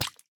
sounds / mob / tadpole / hurt3.ogg
hurt3.ogg